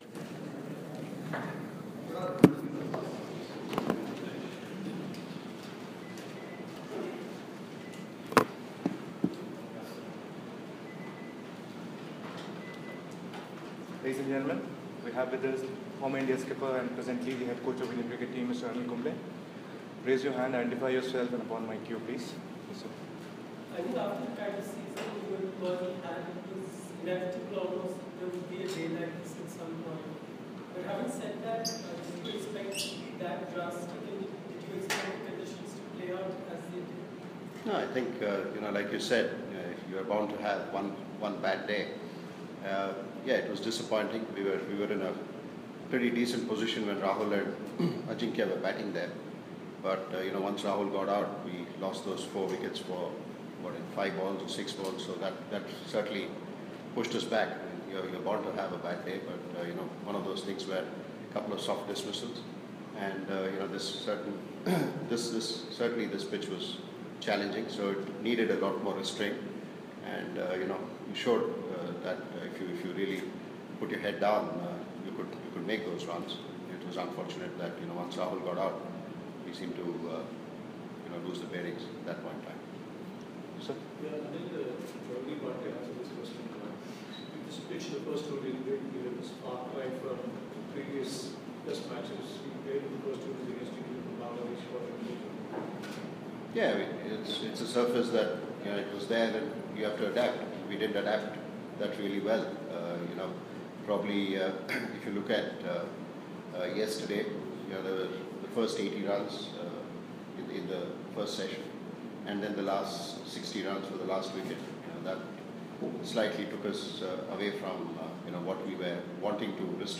LISTEN: Coach Anil Kumble speaks after second day of the Pune Test